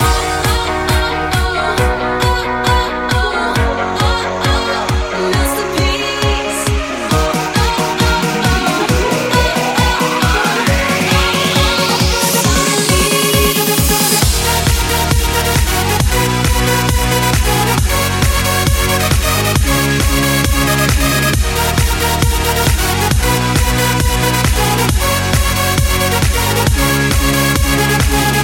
Genere: dance, club, edm, remix